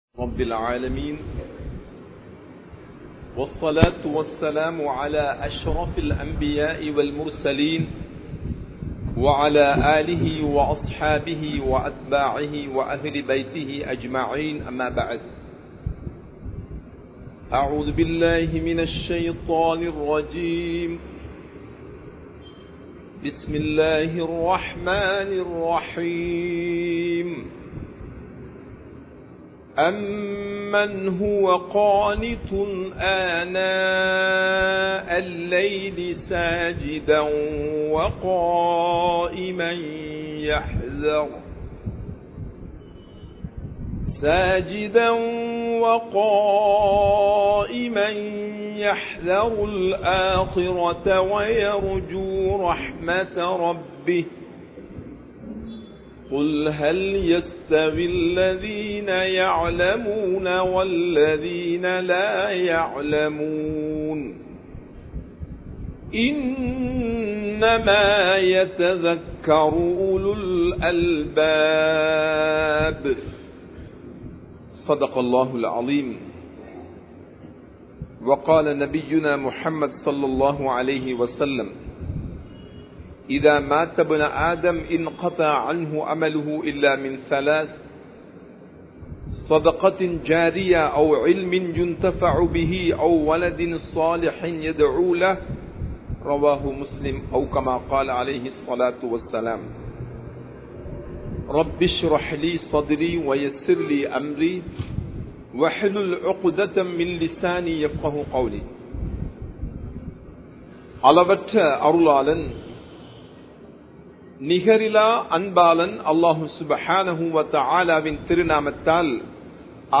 Kalvien Sirappu (கல்வியின் சிறப்பு) | Audio Bayans | All Ceylon Muslim Youth Community | Addalaichenai
Wellampittiya, Sedhawatte, Ar Rahmath Jumua Masjidh